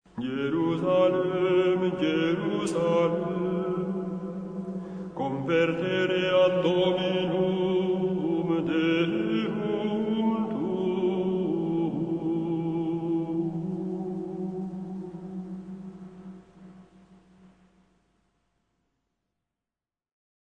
Ascolta questo breve frammento di canto gregoriano.